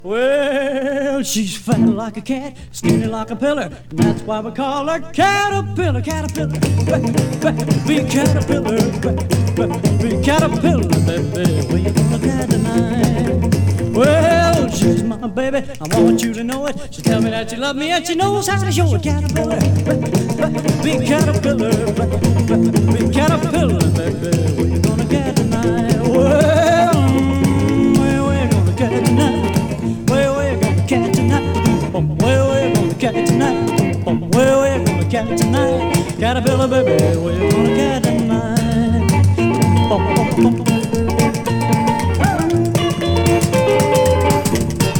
Rock, Rockabilly　UK　12inchレコード　33rpm　Mono